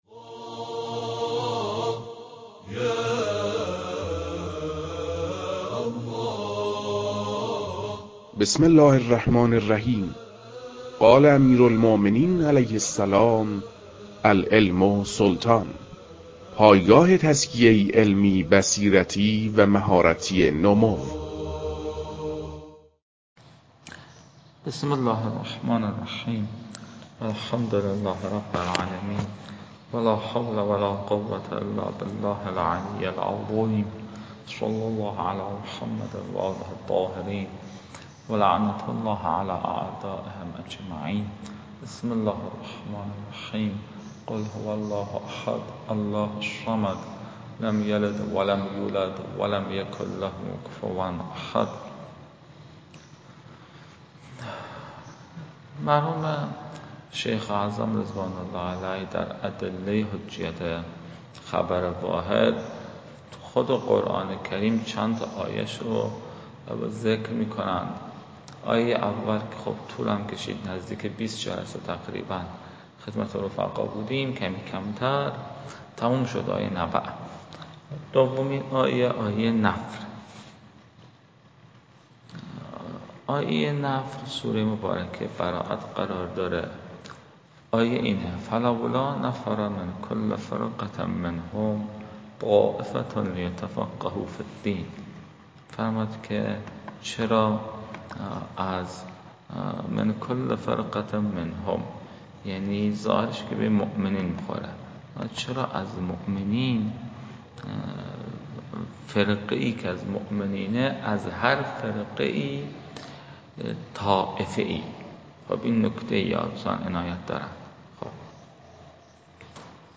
فایل های مربوط به تدریس